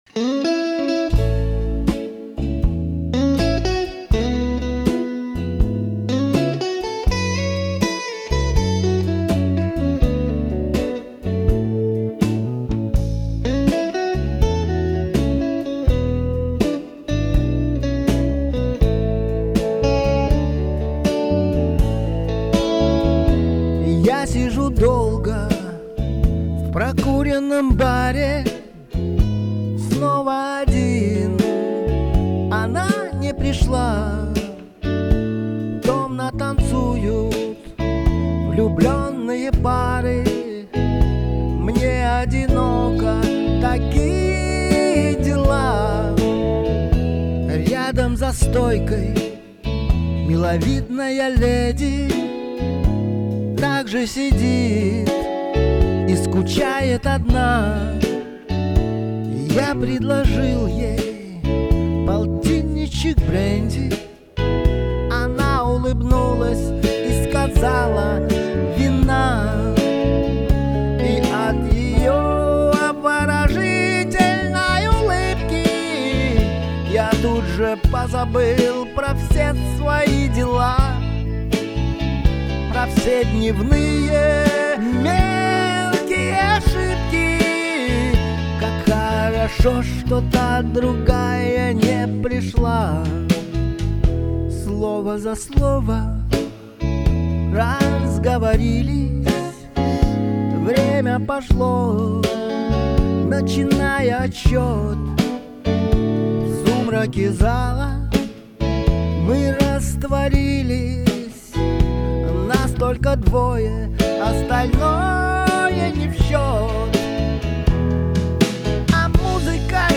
ну,нелегких точно.da_da Учитывая,что это еще и авторская работа у меня нет никаких вопросов.Блюз был блюзом .Не слащавый,а чисто мужской вариант подачи.
это блюз и именно в мажорном ладу.